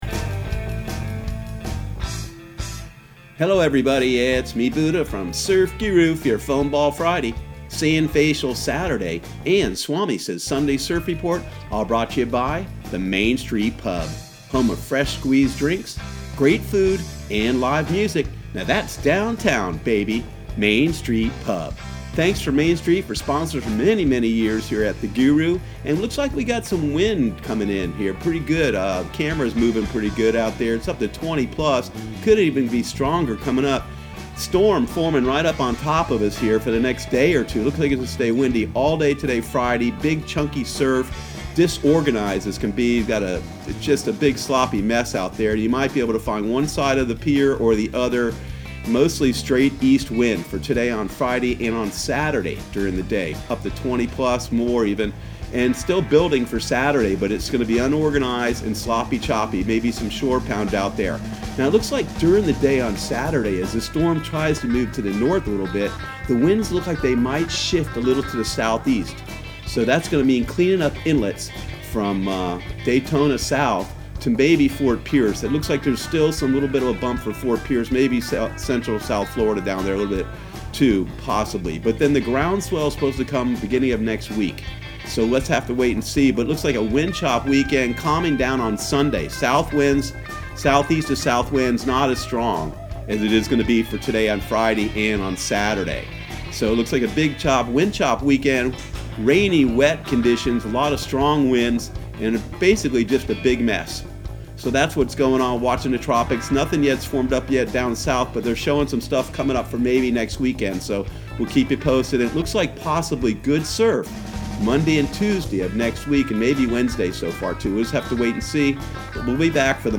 Surf Guru Surf Report and Forecast 09/13/2019 Audio surf report and surf forecast on September 13 for Central Florida and the Southeast.